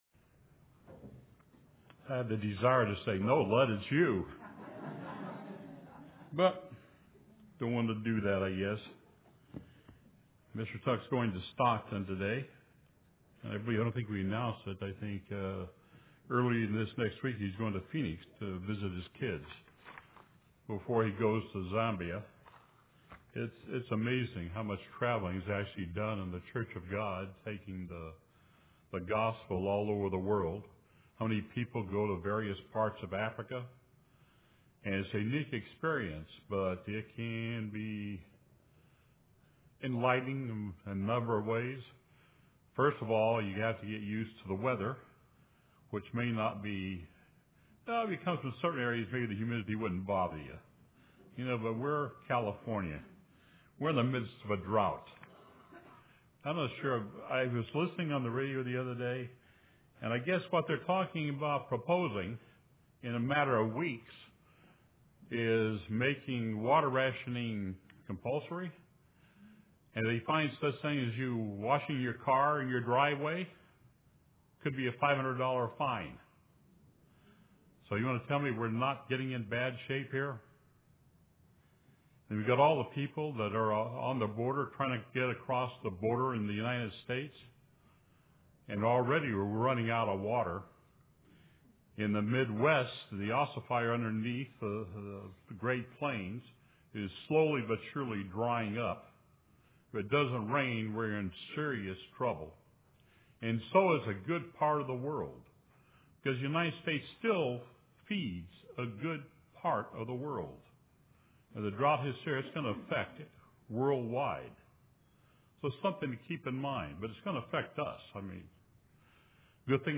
UCG Sermon Notes Partial list of Scriptures: Mat 16:2 He answered and said unto them, When it is evening, ye say, It will be fair weather: for the sky is red.